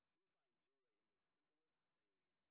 sp07_street_snr10.wav